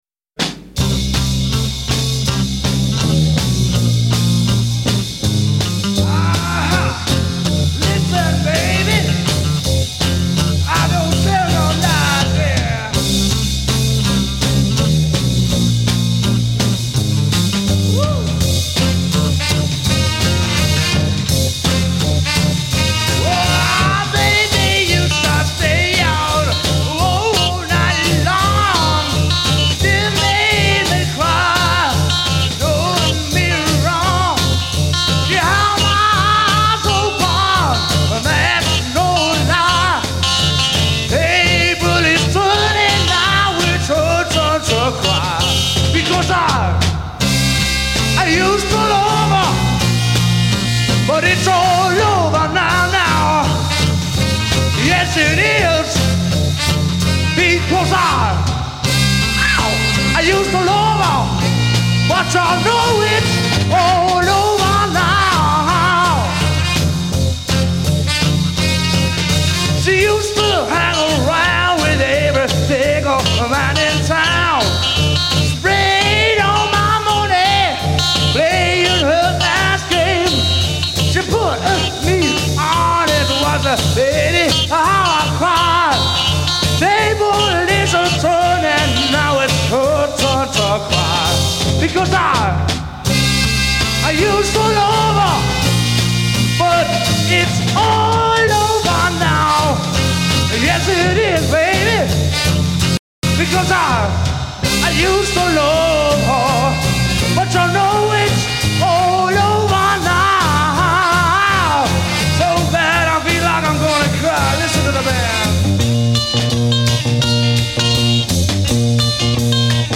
Vocals
Guitar
Bass
Trumpet
Saxophone
Drums